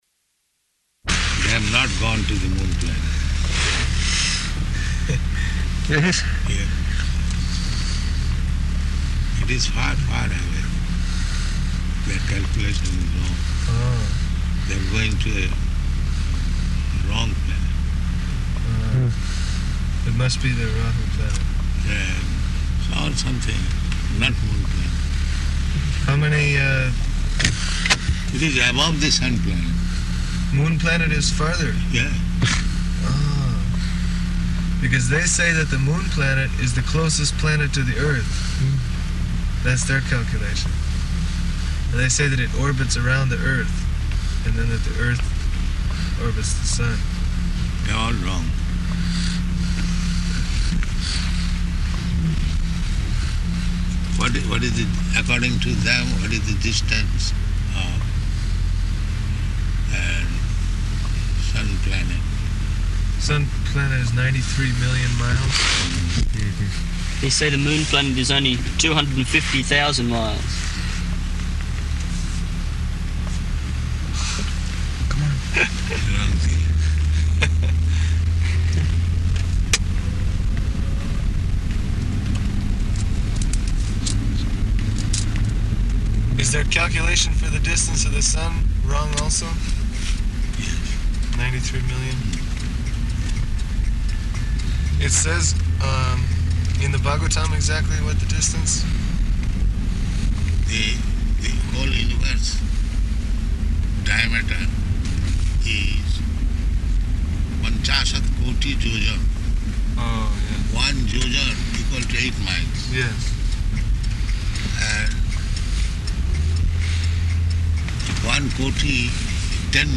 Morning Walk --:-- --:-- Type: Walk Dated: May 12th 1975 Location: Perth Audio file: 750512MW.PER.mp3 [in car] Prabhupāda: They have not gone to the moon planet.